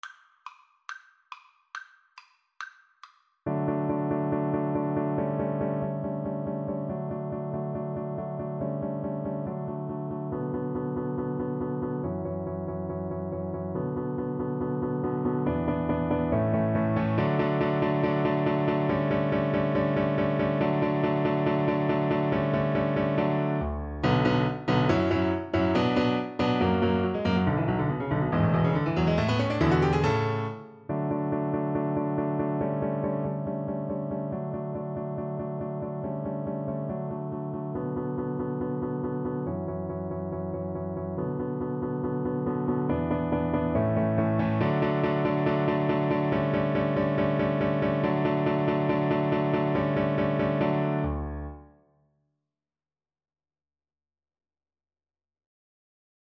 Play (or use space bar on your keyboard) Pause Music Playalong - Piano Accompaniment Playalong Band Accompaniment not yet available transpose reset tempo print settings full screen
~ = 140 Allegro vivace (View more music marked Allegro)
D major (Sounding Pitch) (View more D major Music for Cello )
Classical (View more Classical Cello Music)